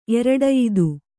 ♪ eraḍayidu